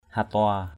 /ha-tʊa:/ (t.) hạn = sécheresse. hatua raya ht&% ry% đại hạn = grande sécheresse. thun hatua aia thu E~N ht&% a`% E~% năm hạn nước khô = l’année de sécheresse...
hatua.mp3